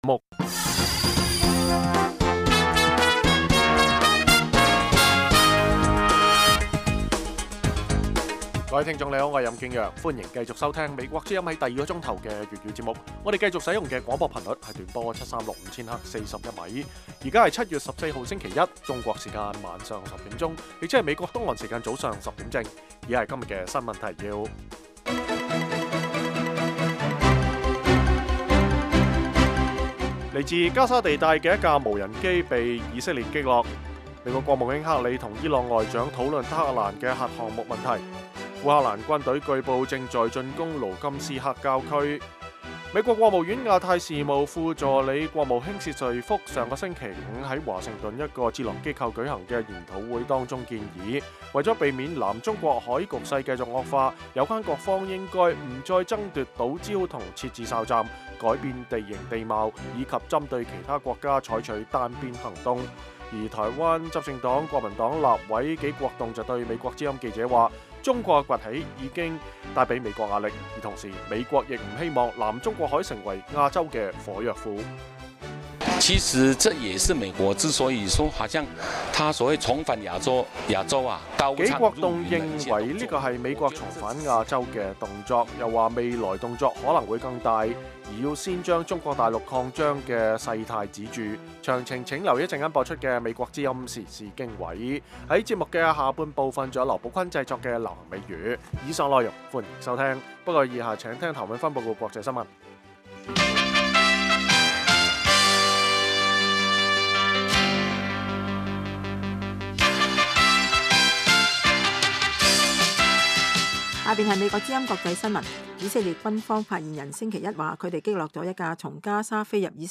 每晚 10點至11點 (1300-1400 UTC)粵語廣播，內容包括簡要新聞、記者報導和簡短專題。